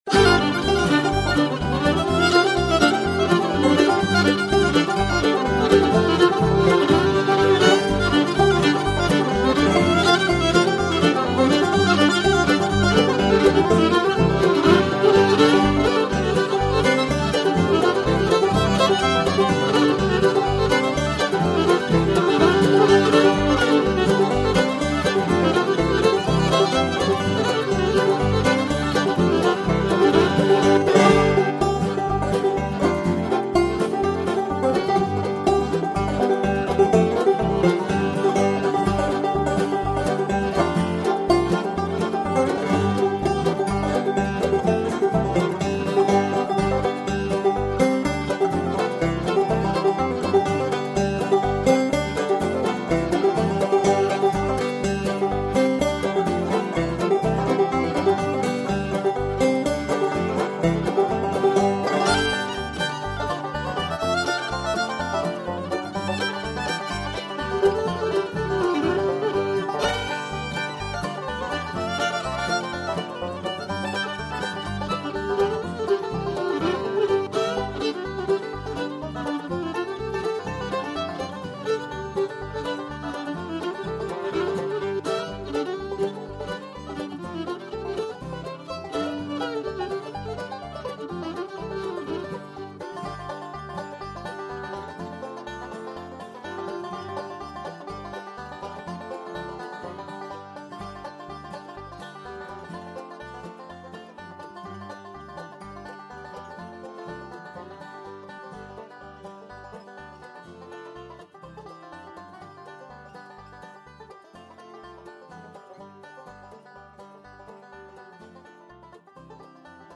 Guitare picking & solo, vocal, old-time banjo
Bluegrass banjo
Fiddle